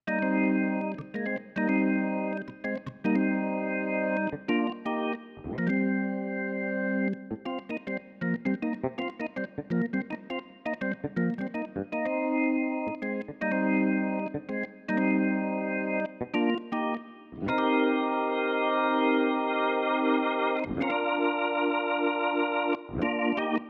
11 organ A.wav